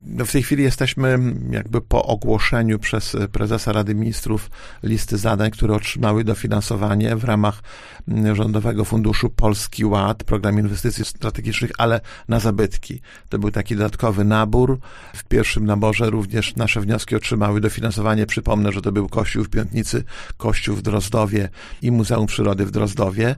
O przebiegu tego procesu mówił w audycji Gość Dnia Radia Nadzieja, starosta powiatu, Lech Marek Szabłowski: